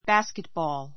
basketball 小 A1 bǽskitbɔːl バ ス ケ ト ボー る ｜ bɑ́ːskitbɔːl バ ー ス ケ ト ボー る 名詞 ❶ バスケットボール We often play basketball after school.